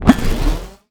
sci-fi_shield_power_deflect_block_03.wav